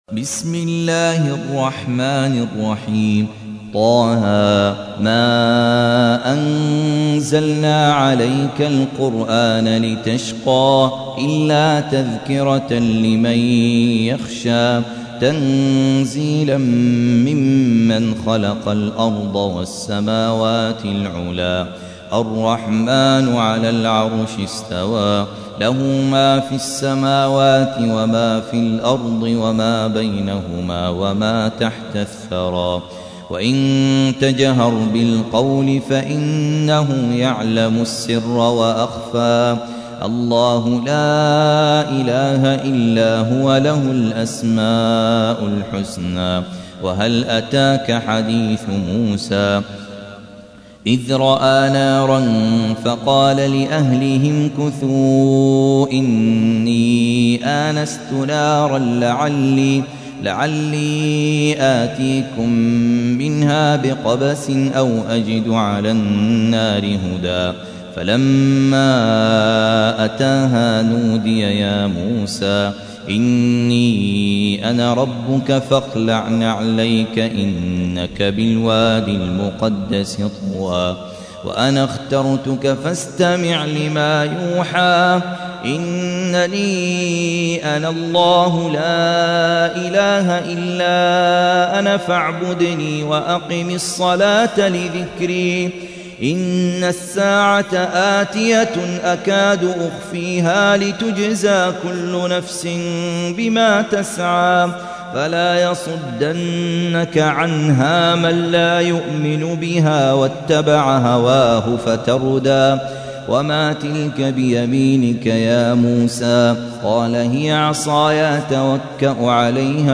20. سورة طه / القارئ